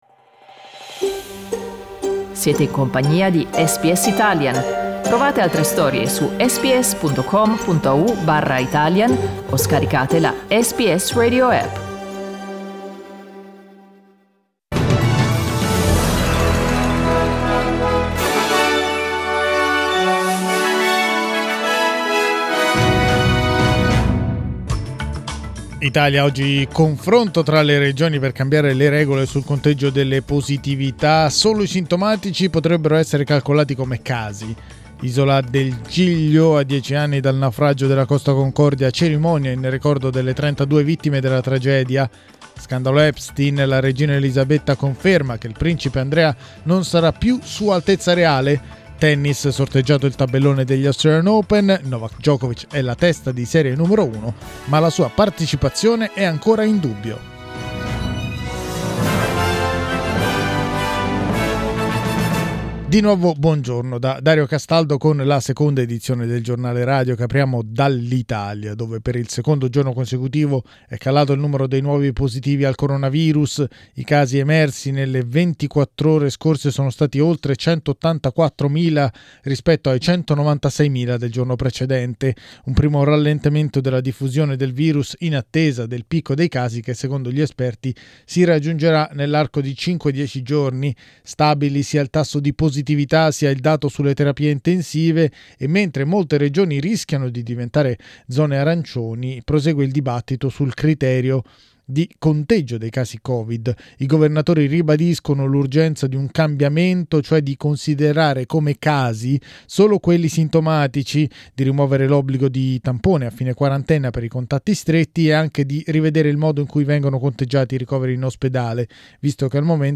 Giornale radio venerdì 14 gennaio 2022
Il notiziario di SBS in italiano.